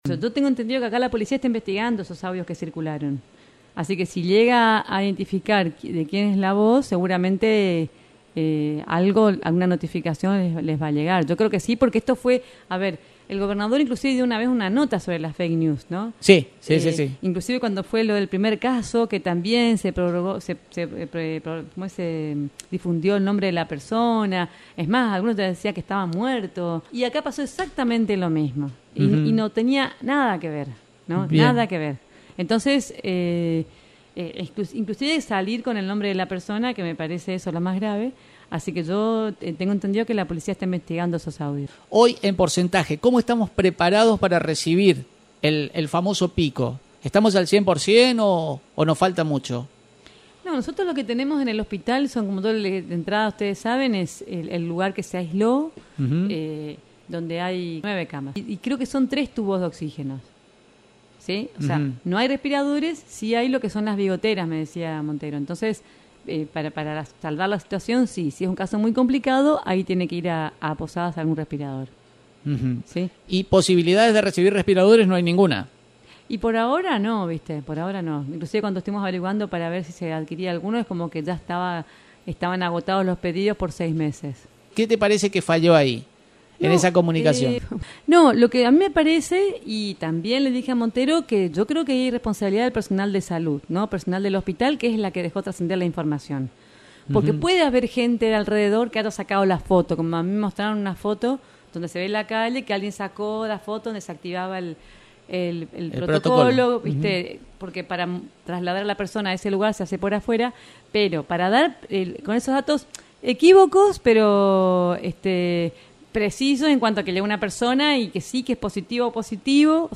La Intendente Municipal María Eugenia Safrán habló de las Fake News que circularon con la activación del protocolo de COVID-19 en el hospital local y se mostró molesta con el accionar que dio a conocer datos del paciente que, a posteriori, mostró resultado negativo para coronavirus.